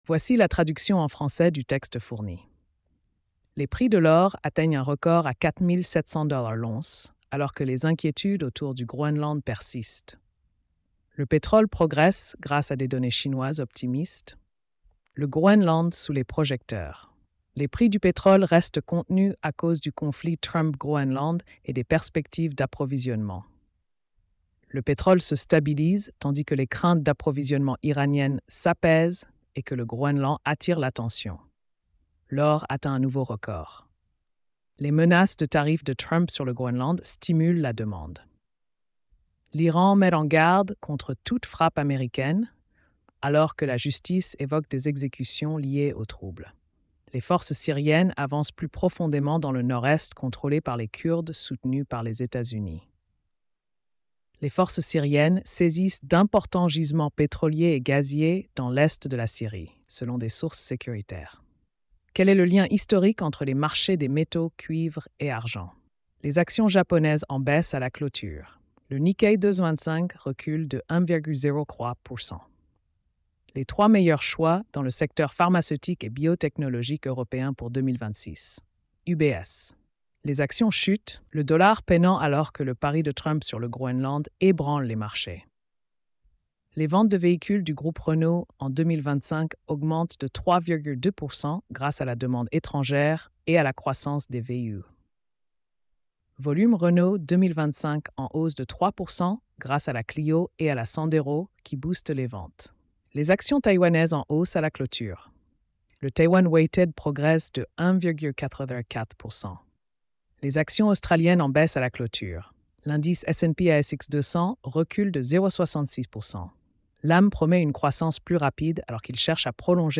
🎧 Résumé économique et financier.